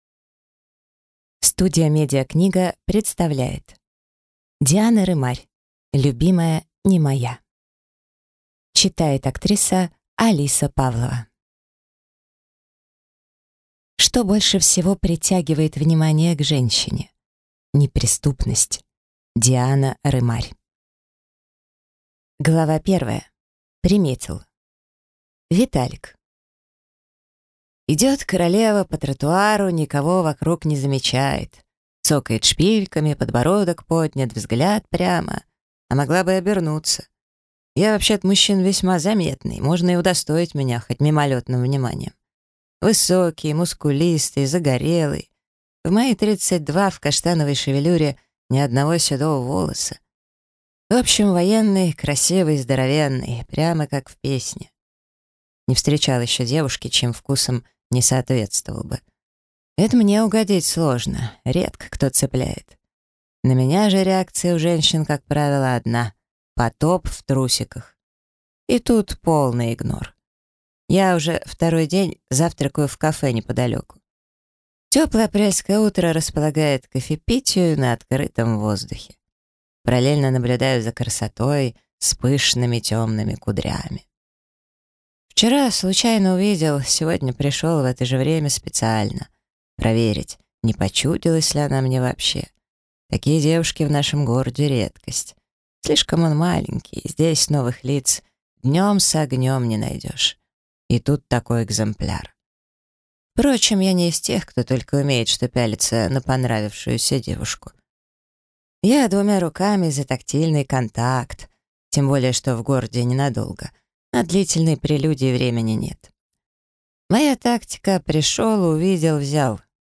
Аудиокнига Любимая, (не) моя | Библиотека аудиокниг